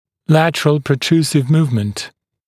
[‘lætərəl prə’truːsɪv ‘muːvmənt][‘лэтэрэл прэ’тру:сив ‘му:вмэнт]переднебоковое движение (напр. нижней челюсти при клыковом ведении)